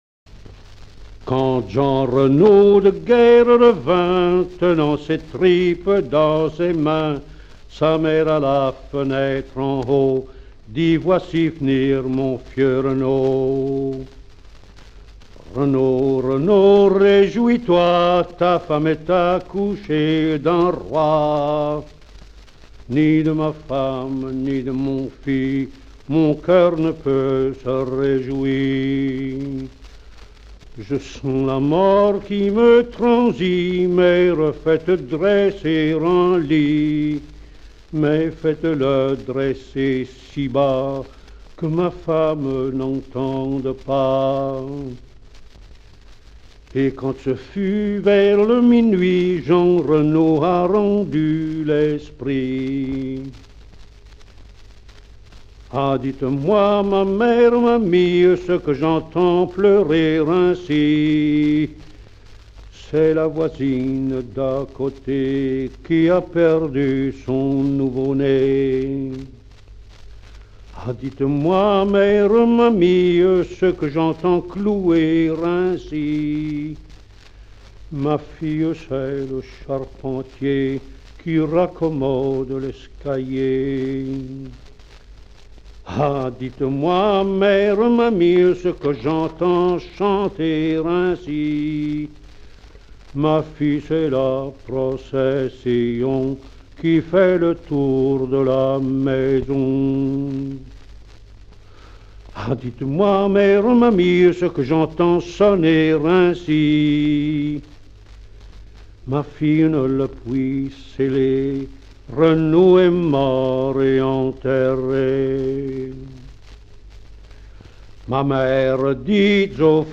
Type : chanson narrative ou de divertissement | Date : 1952
Mode d'expression : chant Lieu : Ixelles Collecteur(s